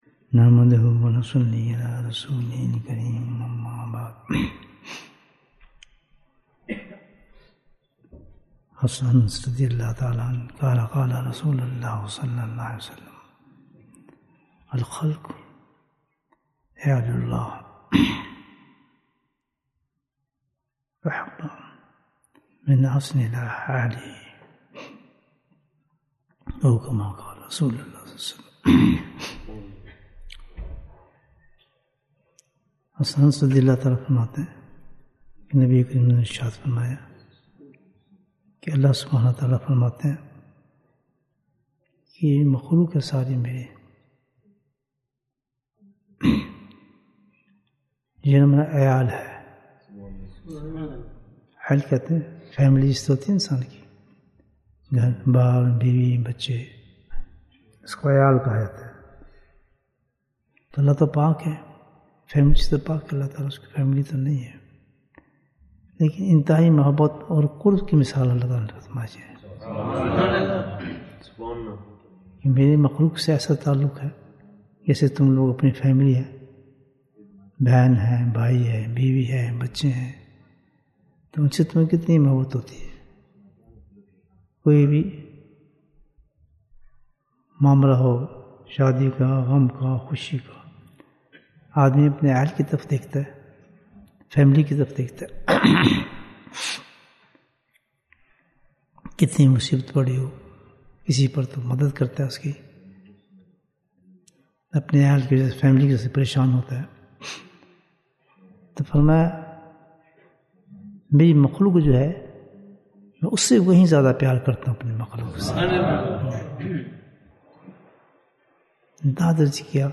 Bayan, 45 minutes 1st April, 2023 Click for English Download Audio Comments Why Does the First Asharah start with Rahmah?